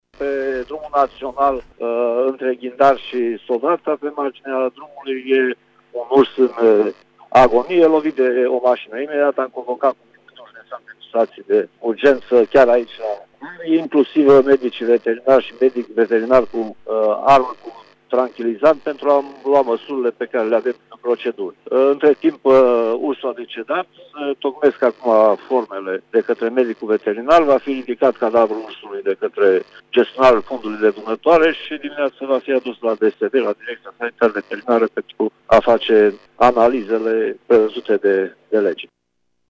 Animalul a decedat, spune prefectul Mircea Dușa: